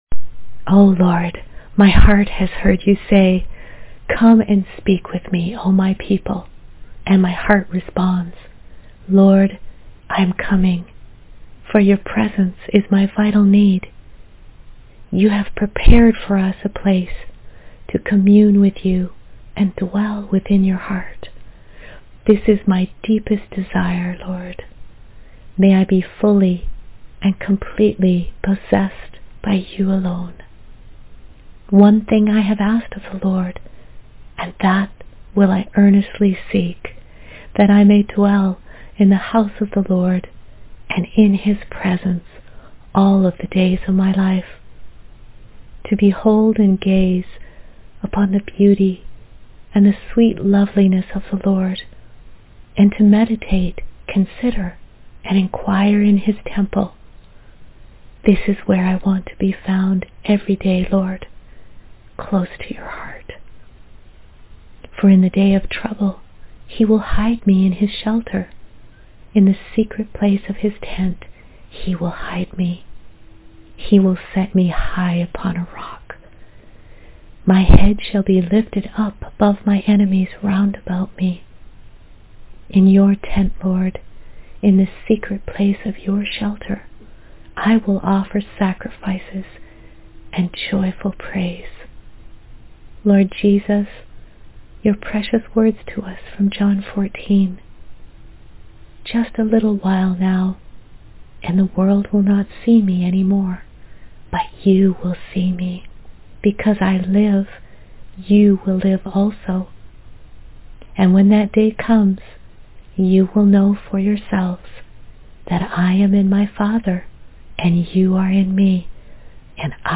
Audio: Prayer and verse: “Dwell”